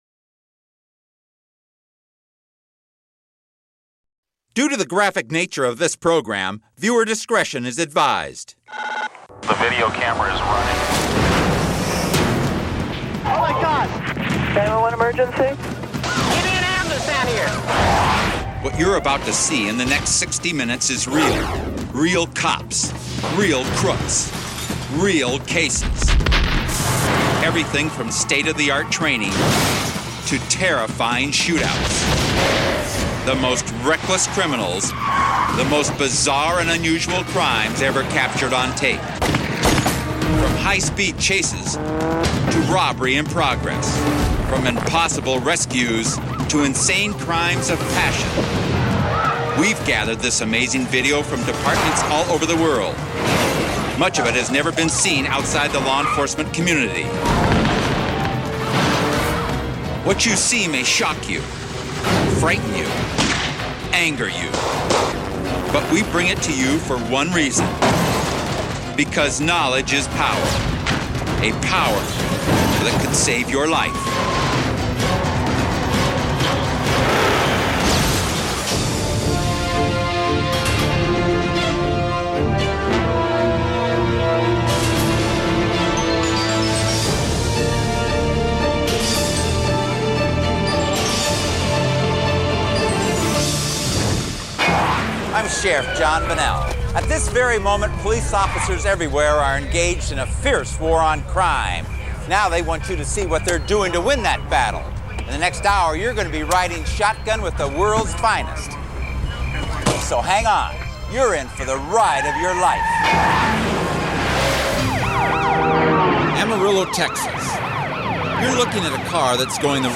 All advertisements are strategically placed only at the beginning of each episode, ensuring you can immerse yourself fully in every investigation, every revelation, and every emotional moment without advertising cuts disrupting the flow of our true crime storytelling.